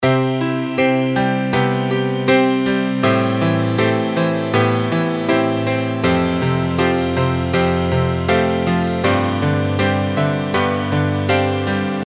描述：钢琴与钢琴的旋律和弦
Tag: 80 bpm Acoustic Loops Piano Loops 2.02 MB wav Key : Unknown